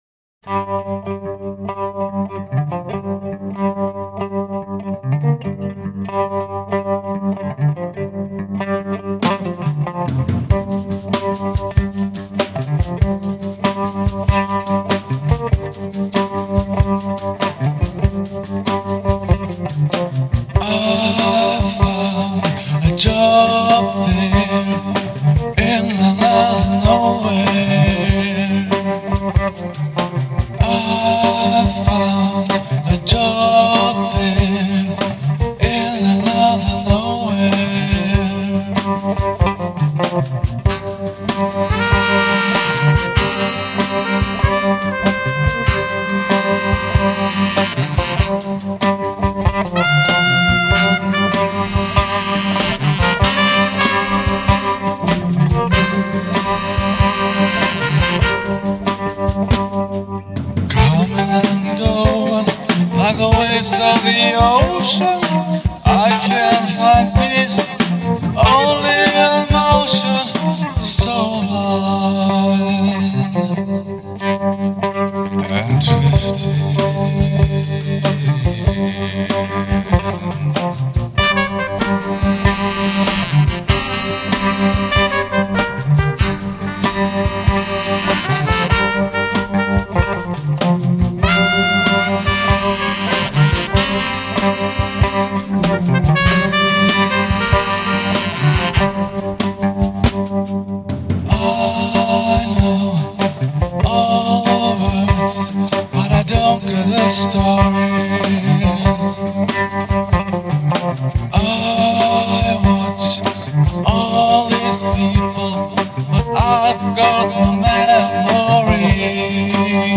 voice
guitars, samples, percussion, electronica
bass guitar
drums
trumpet (1, 2, 6)
tenor sax (1)